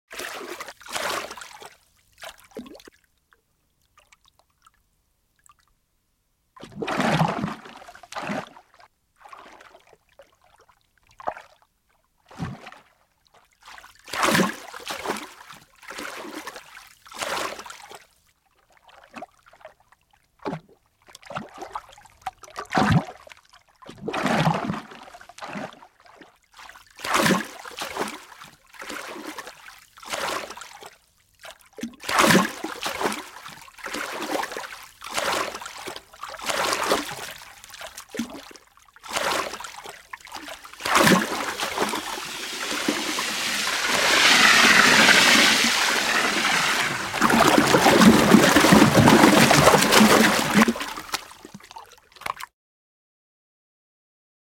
دانلود صدای حیوان آبی 13 از ساعد نیوز با لینک مستقیم و کیفیت بالا
جلوه های صوتی